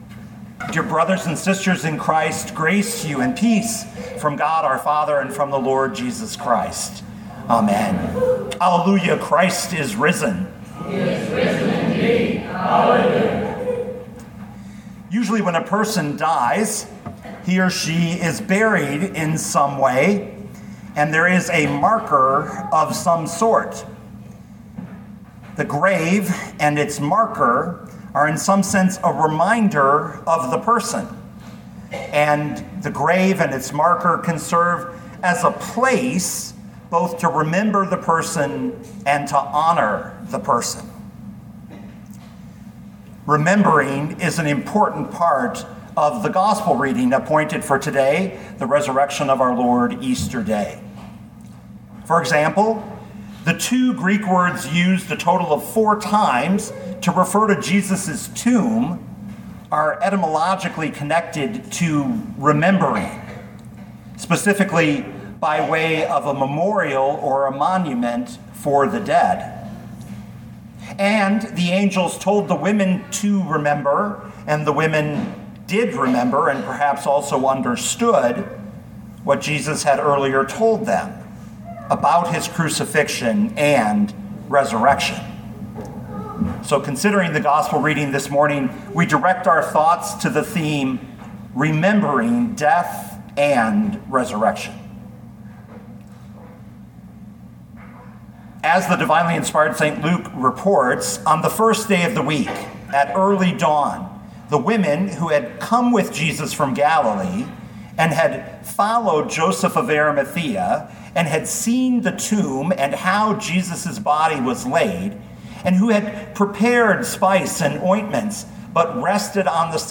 2022 Luke 24:1-12 Listen to the sermon with the player below, or, download the audio.